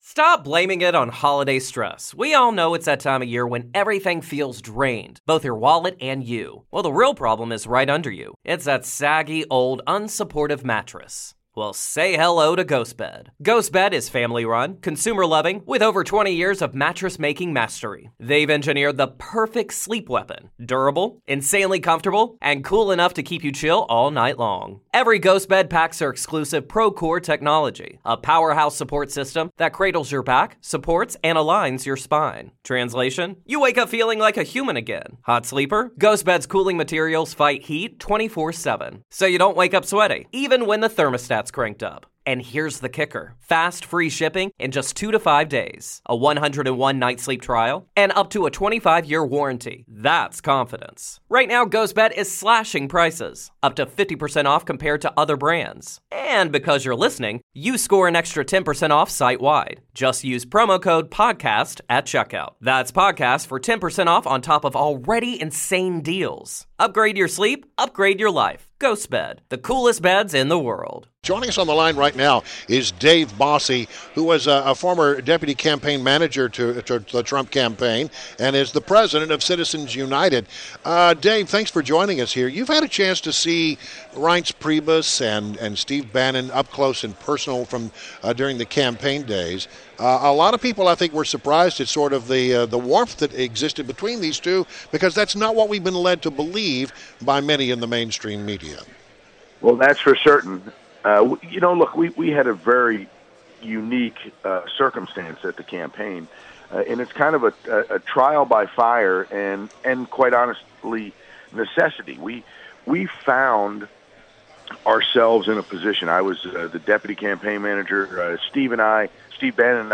WMAL Interview - DAVE BOSSIE - 2.24.17